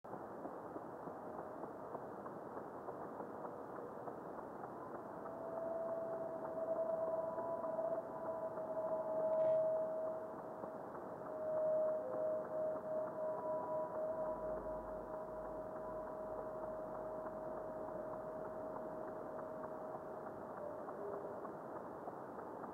Dim meteor with weak but evident radio reflection.